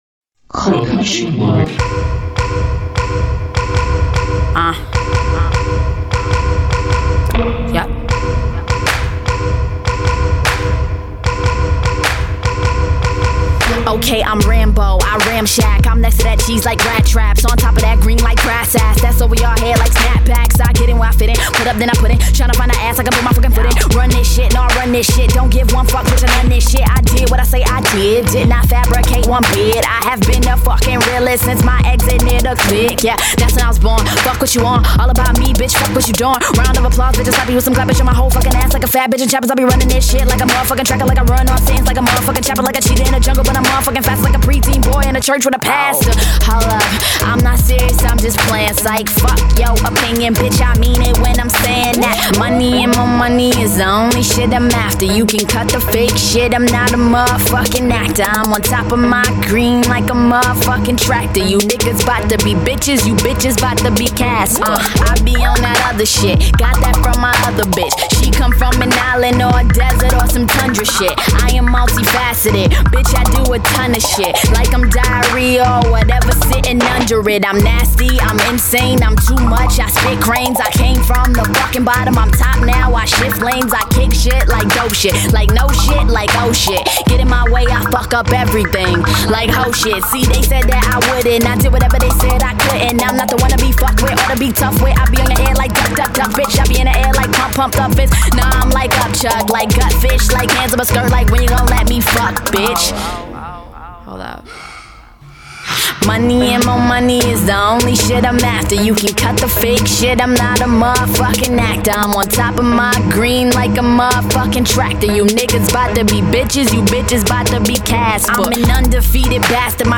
a particularly creepy